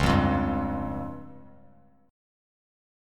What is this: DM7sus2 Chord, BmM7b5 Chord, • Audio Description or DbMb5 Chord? DbMb5 Chord